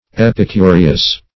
Epicureous \Ep`i*cu*re"ous\, a.
epicureous.mp3